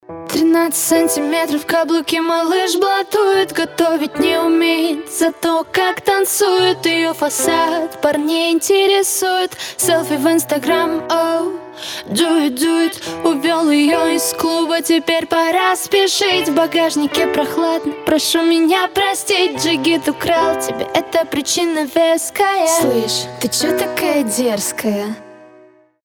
• Качество: 320, Stereo
красивые
женский вокал
саундтреки
спокойные
Cover
Acoustic